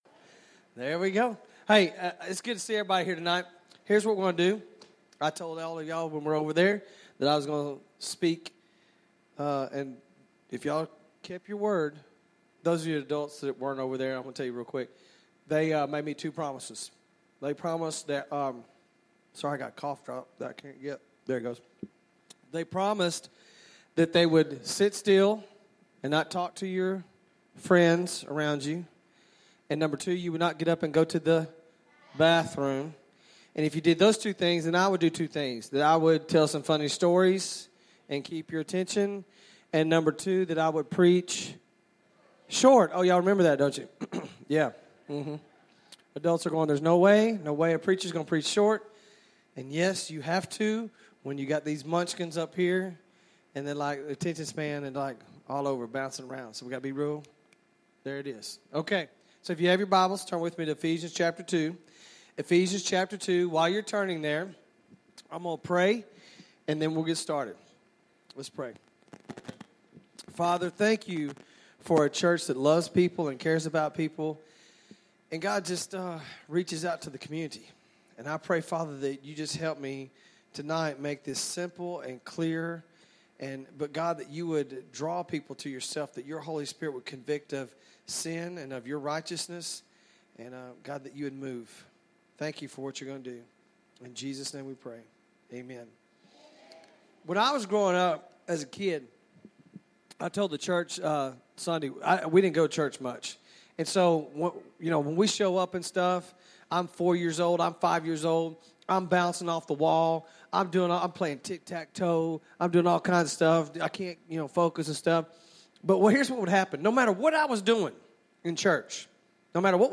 Service Type: Revival